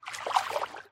Minecraft Dolphin Swim Sound Effect Free Download
Minecraft Dolphin Swim